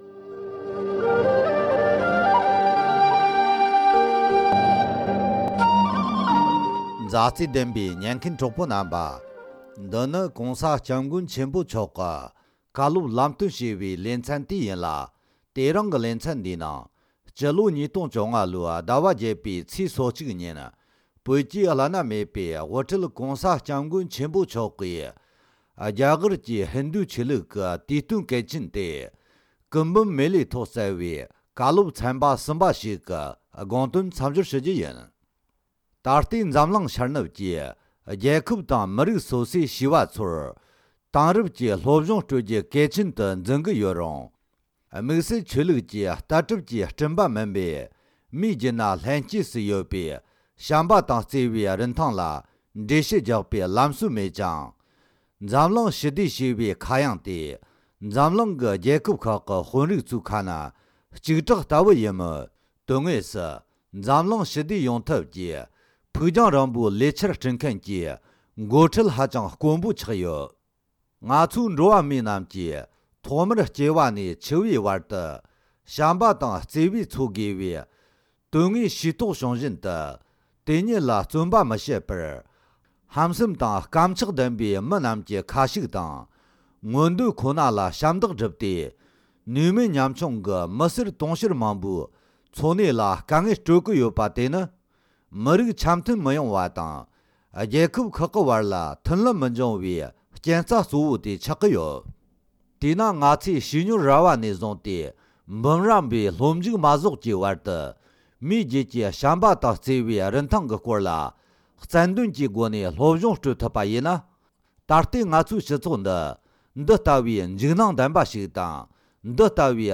ཧིན་དྷུ་ཆོས་ལུགས་ཀྱི་དུས་སྟོན་ཐོག་བསྩལ་བའི་བཀའ་སློབ།
༧གོང་ས་མཆོག་གིས་ཀུམ་མེ་ལ་ཞེས་པའི་ཧིན་དྷུ་ཆོས་ལུགས་ཀྱི་དུས་སྟོན་ཐོག་བསྩལ་བའི་སློབ།